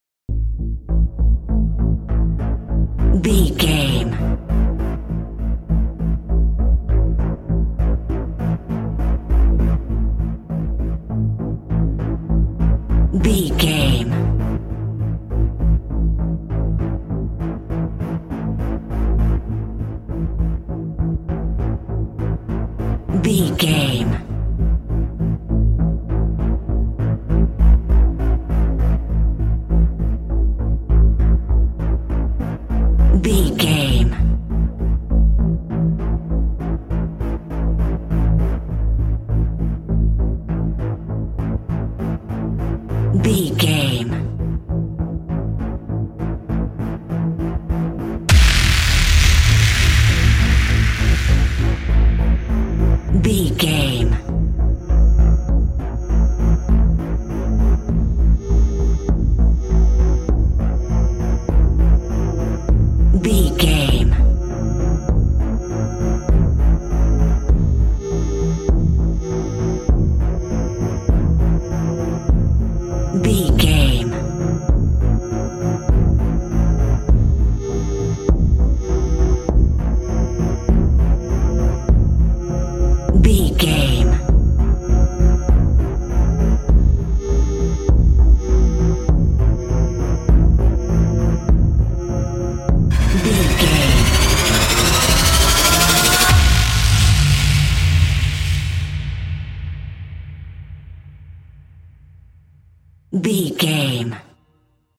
Aeolian/Minor
synthesiser
percussion